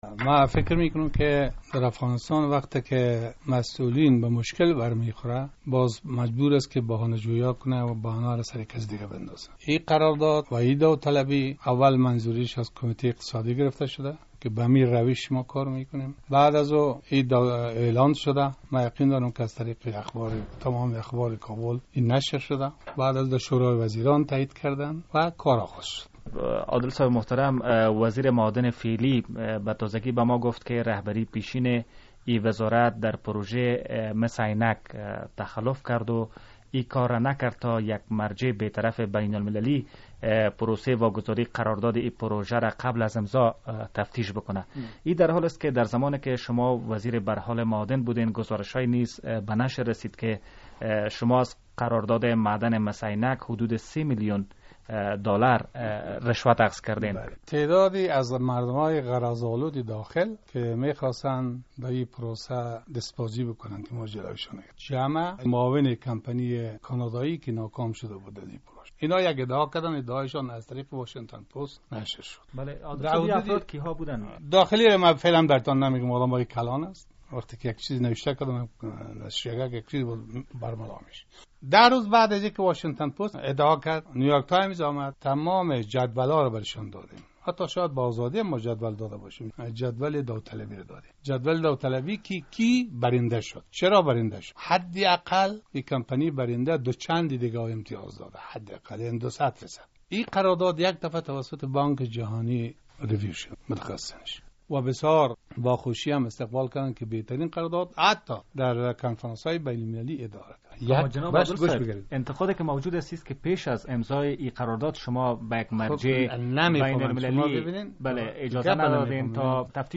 وزیر پیشین معادن افغانستان اختلاس و نواقص در قرار داد های را که در زمان او در بخش معادن امضا شده است رد می کند. انجنیر محمد ابراهیم عادل امروز (دوشنبه) در مصاحبه با رادیو آزادی در این مورد سخن گفت.